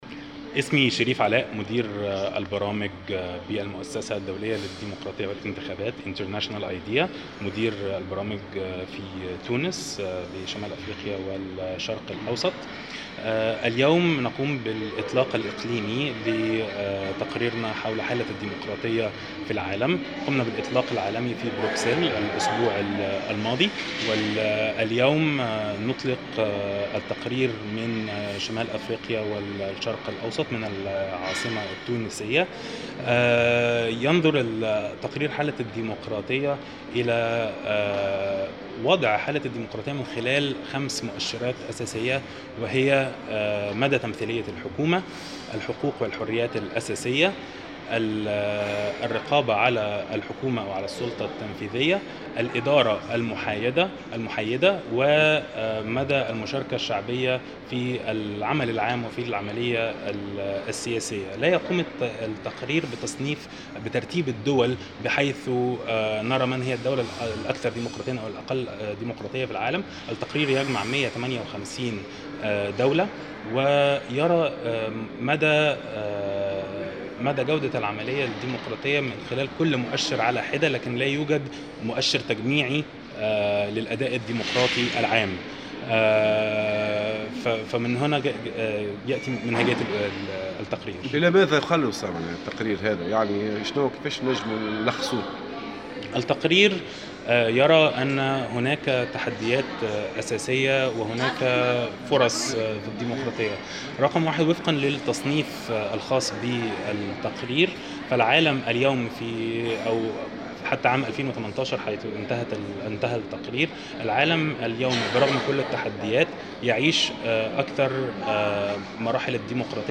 واضاف في تصريح لمراسلة "الجوهرة اف ام" أن تقرير المؤسسة لسنة 2019 حول حالة الديمقراطية العالمية، صنف تونس من بين الـ25 بالمائة من الدول التي تعيش ديمقراطية. وأكد ذات التقرير أن تونس شهدت منذ الثورة تطورا في جميع المؤشرات المرتبطة بالديمقراطية إلى جانب تجاوزها للمتوسط العالمي في معظم المؤشرات و تعتبر تونس من افضل 25% دولة أداء في العالم.